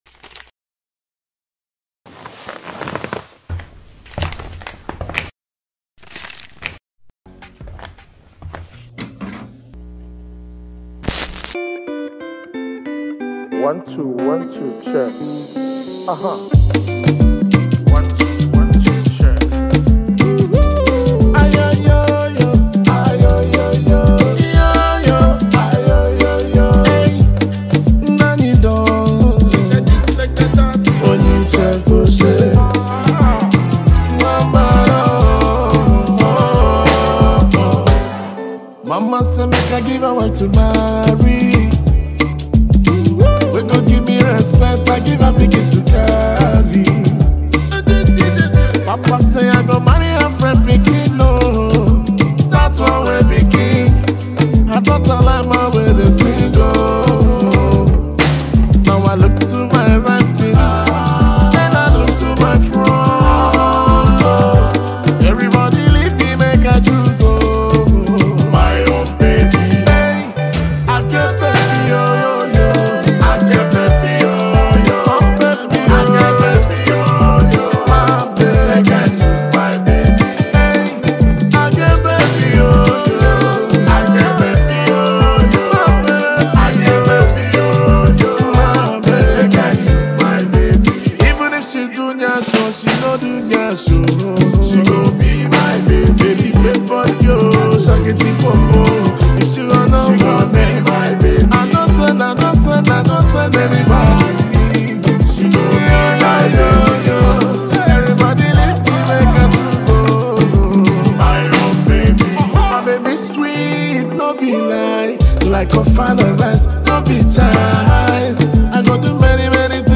Highly Rated Nigerian highlife singer